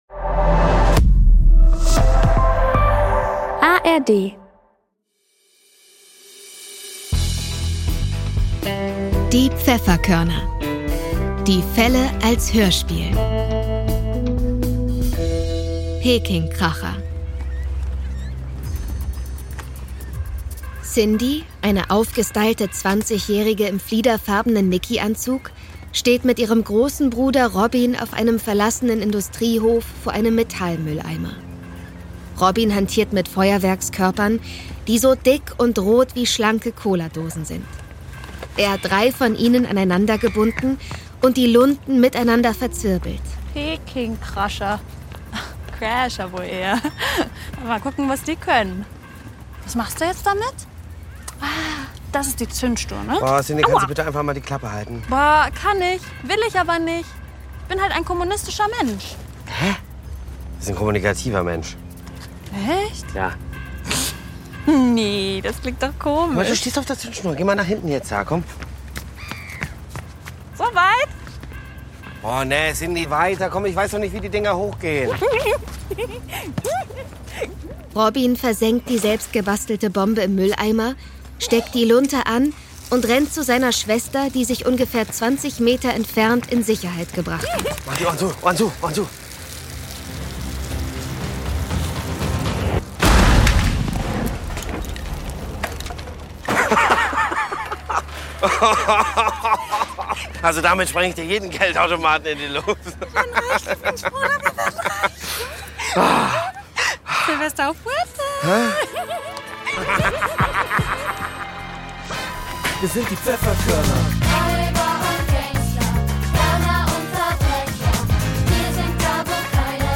Peking-Kracher (24/26) ~ Die Pfefferkörner - Die Fälle als Hörspiel Podcast